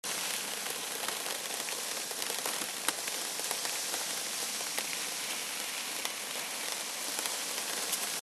На этой странице собраны звуки песчаной бури – от далёкого гула до оглушительного вихря.
Шум песчаных частиц, ударяющихся во время шторма